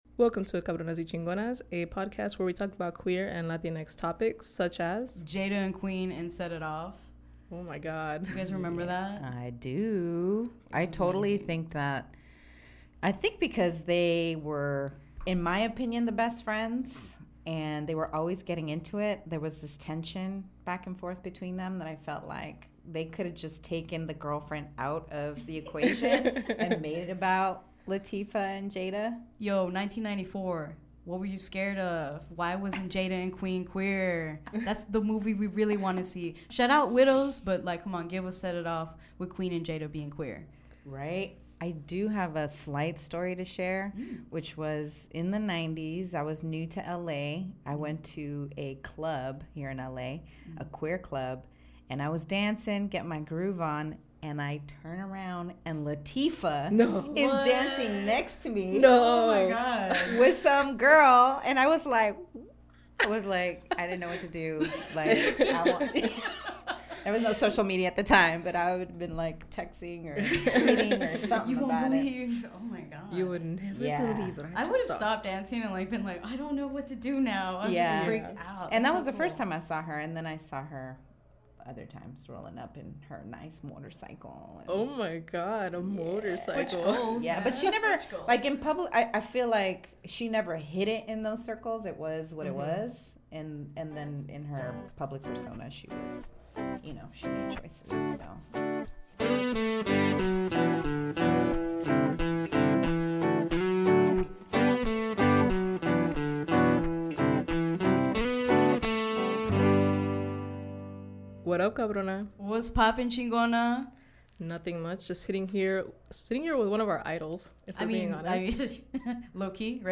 We are joined in the studio by the queer Latina director of our dreams